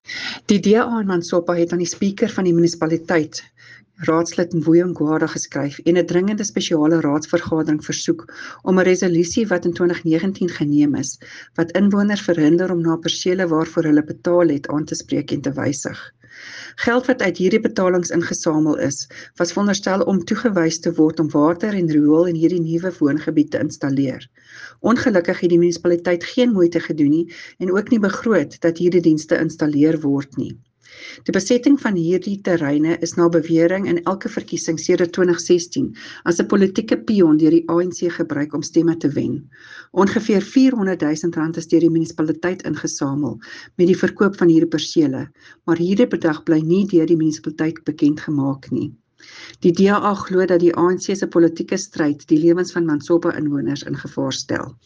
Afrikaans soundbites by Cllr Tania Halse and Sesotho by Karabo Khakhau MP.